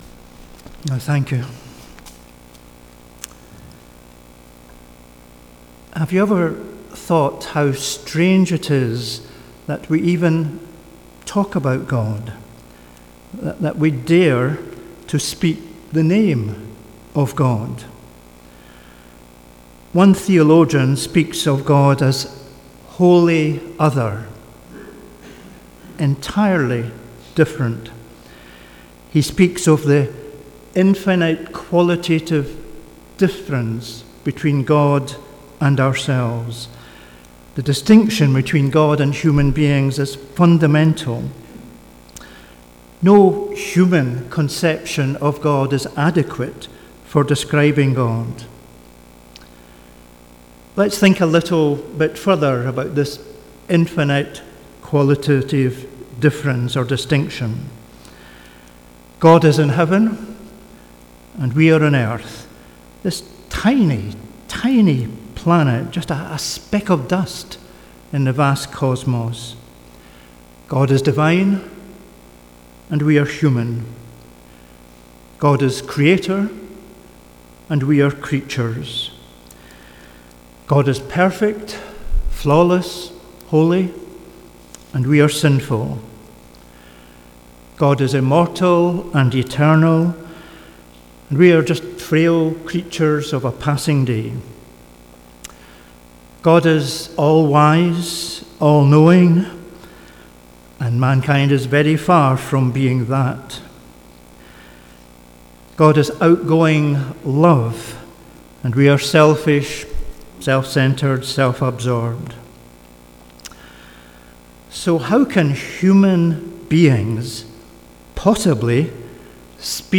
Philippians Passage: Philippians 3:1-11 Service Type: Sunday Morning « Christ in your life Right relationships